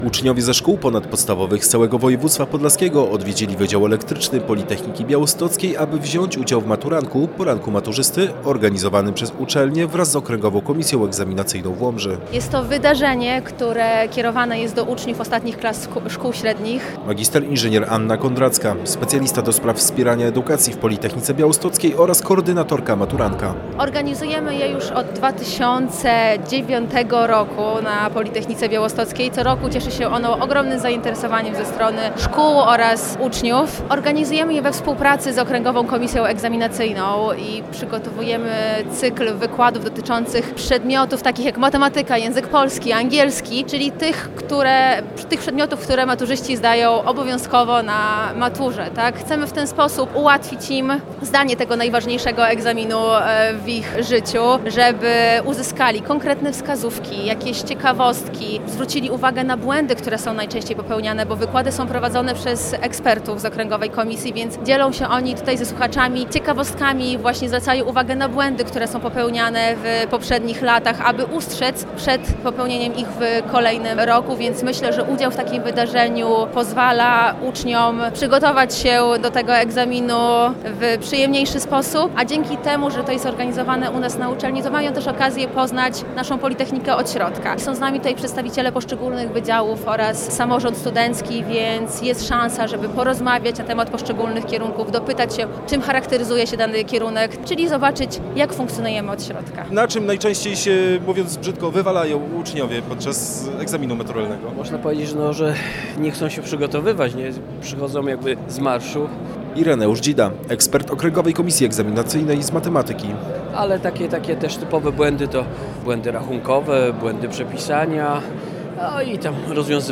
Na Wydziale Elektrycznym Politechniki Białostockiej odbył się MATURANEK – Poranek Maturzysty, czyli jedno z największych regionalnych wydarzeń edukacyjnych skierowanych do przyszłorocznych maturzystów.